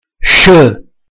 sh = si pronuncia come "sh" inglese("sc" di pascere)
pronuncia della sh.mp3